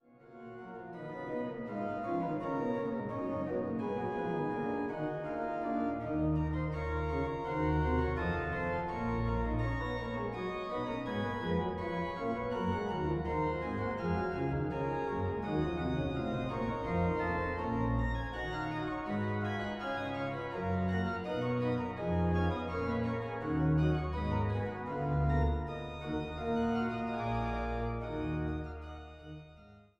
Einweihungskonzertes vom 03.11.2000